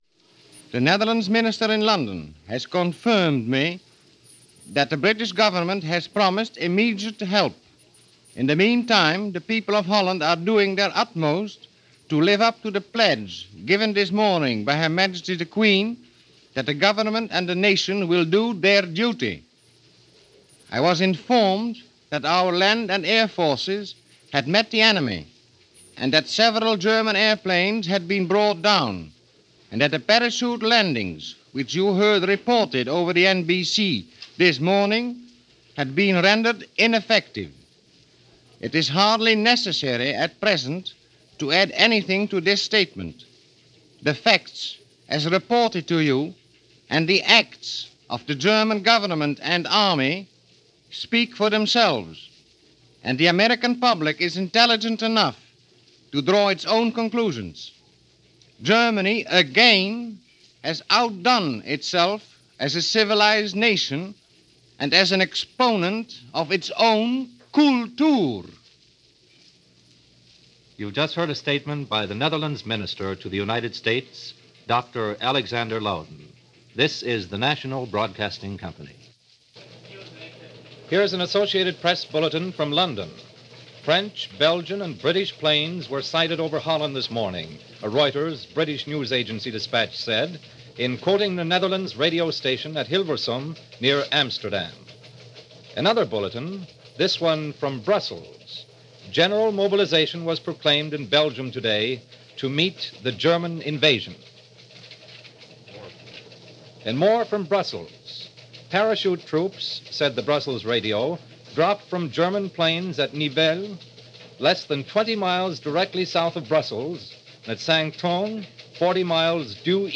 May 10, 1940 - Invasion of the Low Countries - bulletins and special reports on the invasion of Belgium, Luxembourg And The Netherlands.